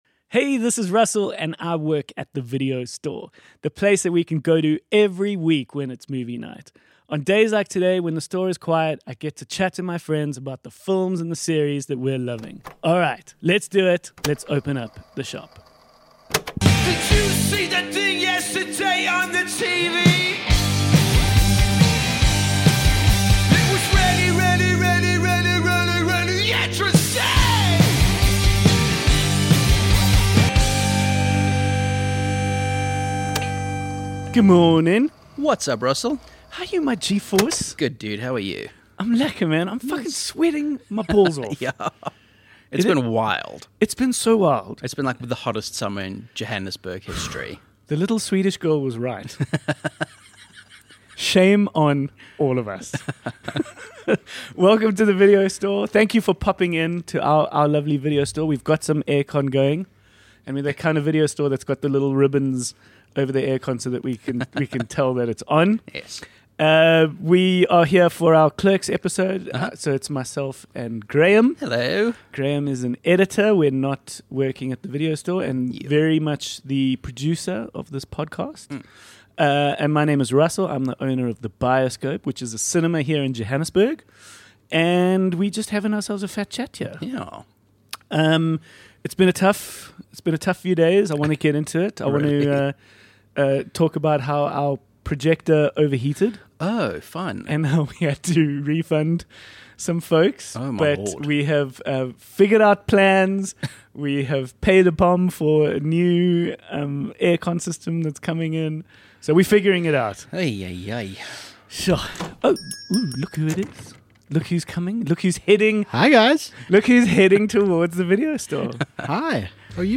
A weekly chat amongst friends working a shift at your local video store.